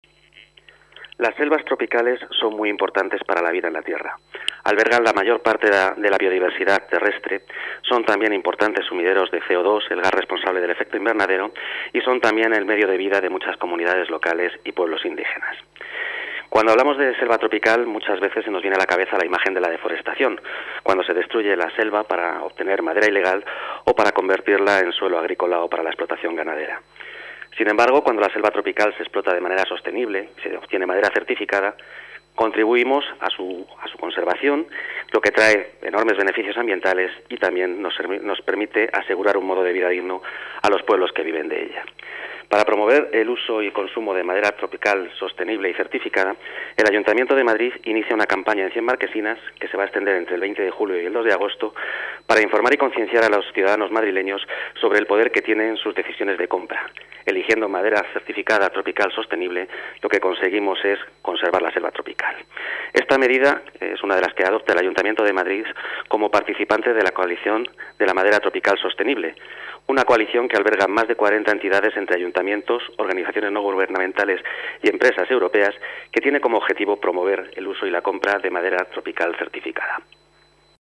Nueva ventana:Declaraciones de Ángel Sánchez, director general del Instituto Municipal de Consumo